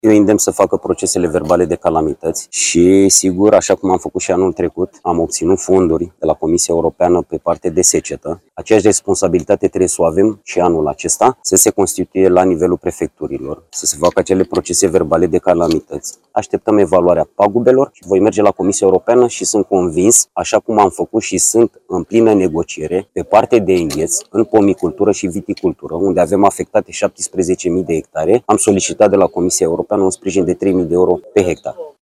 Florin Barbu, Ministrul Agriculturii: „Am obținut fonduri de la Comisia Europeană pe partea de secetă”
25iul-17-CORESP-AR-voce-Florin-Barbu-despagubiri-seceta-.mp3